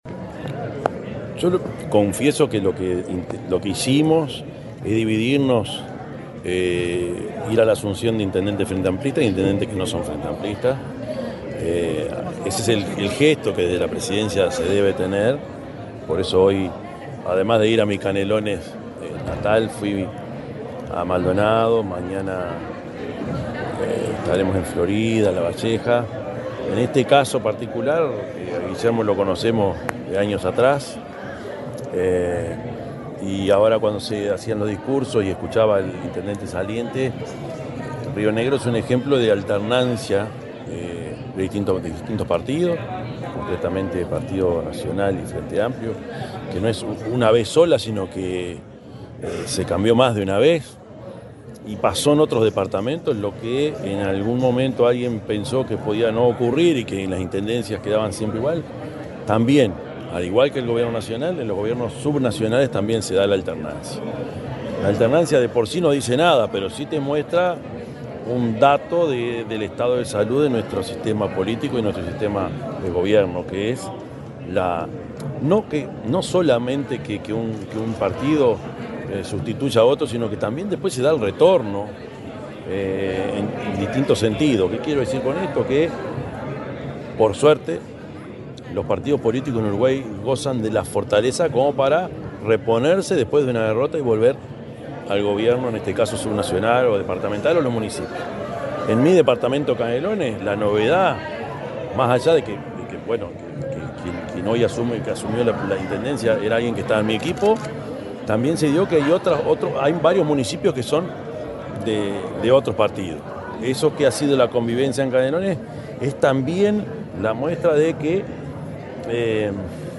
El presidente de la República, Yamandú Orsi, dialogó con la prensa en Río Negro, luego de asistir a la ceremonia de asunción del intendente Guillermo